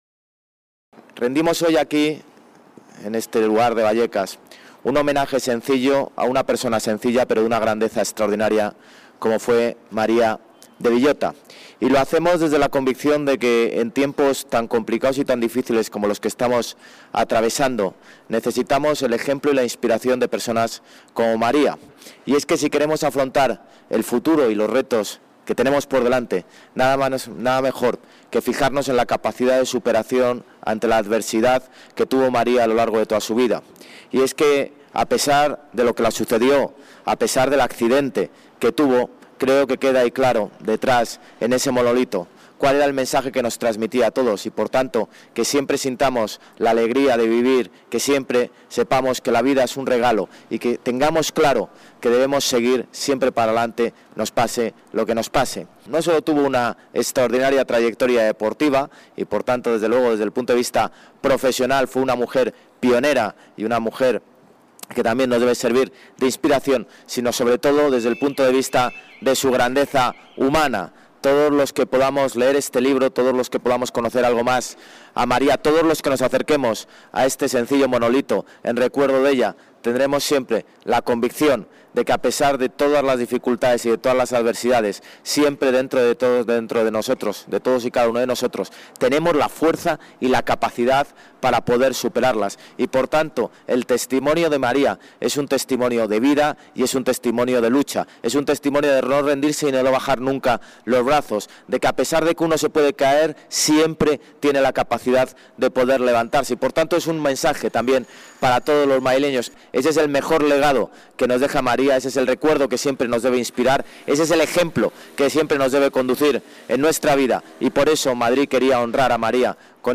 Inauguración del monolito en homenaje a María de Villota La colocación de este monumento fue aprobada por unanimidad en el Pleno del distrito de Puente de Vallecas de noviembre de 2018.